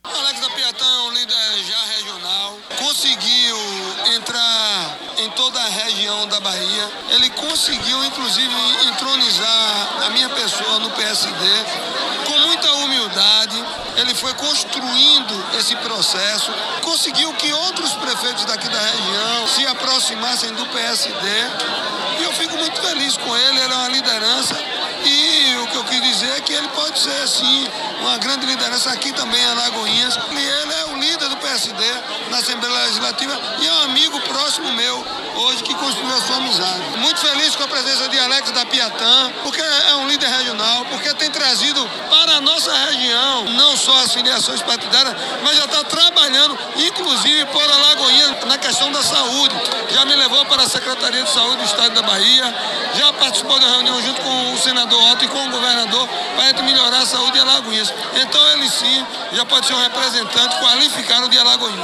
O ato de filiação aconteceu na manhã de sexta-feira, 10, na Câmara Municipal de Alagoinhas.